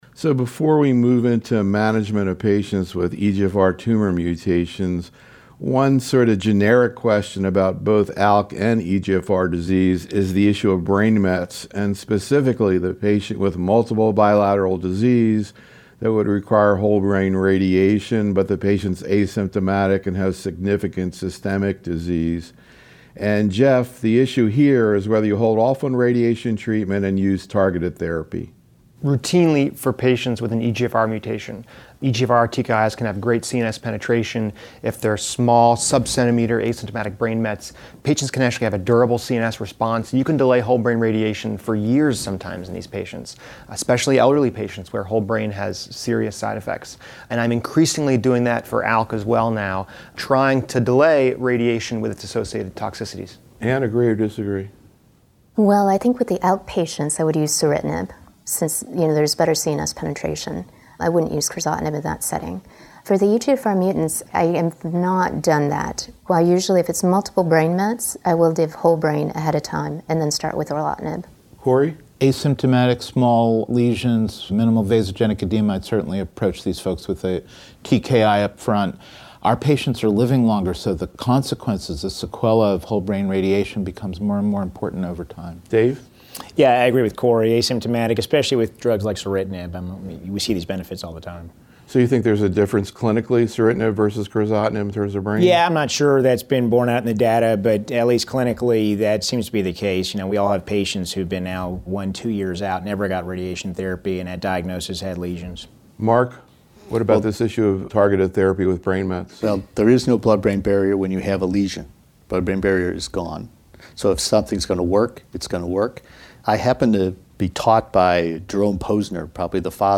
In these audio proceedings from a closed Think Tank meeting held in January 2015, the invited faculty discuss and debate some of the key clinical management issues in lung cancer as well as promising research strategies in this area. The roundtable discussion focused on key presentations and papers and actual cases managed in the practices of the faculty in which these data sets factored into their decision-making.